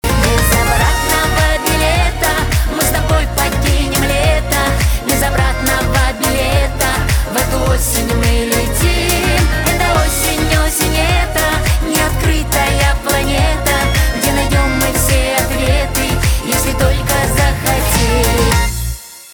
эстрада
битовые , аккордеон